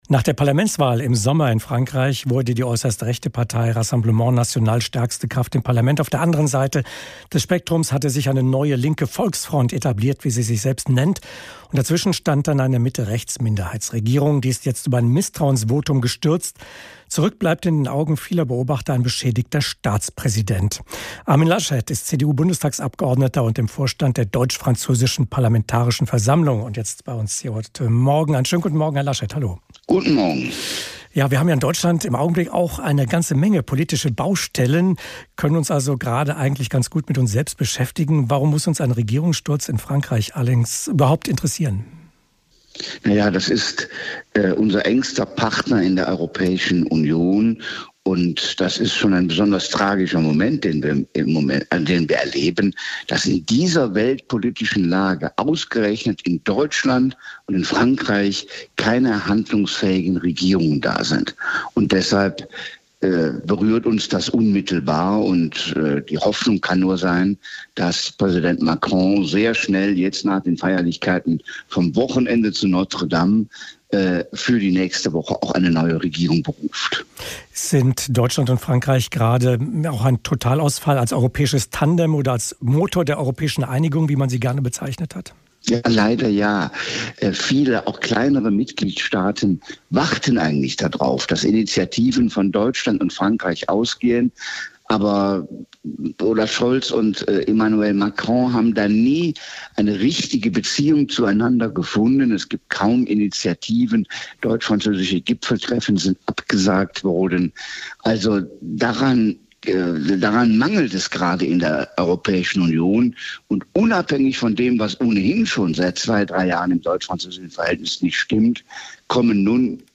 Welche Unterschiede es zwischen den Situationen in beiden Ländern gibt und was es aus seiner Sicht jetzt braucht, hat er im Gespräch mit hr INFO erklärt.